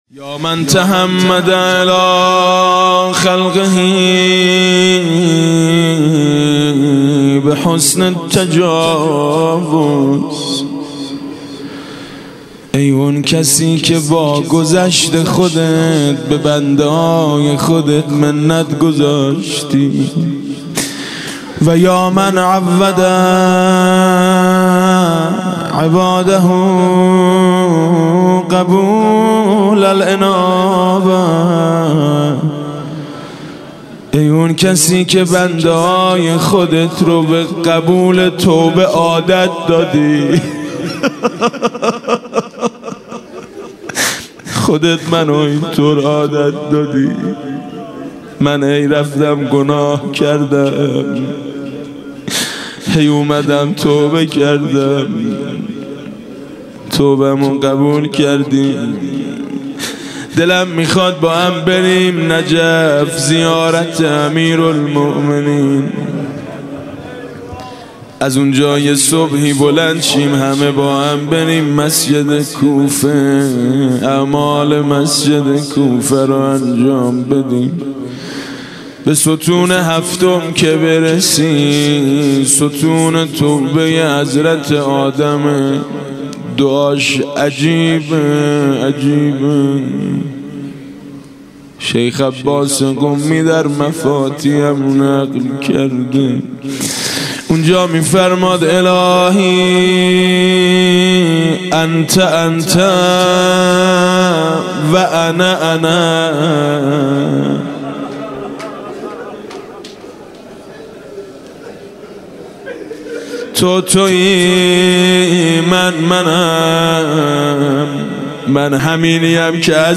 مناسبت : شب پنجم رمضان
مداح : میثم مطیعی قالب : مناجات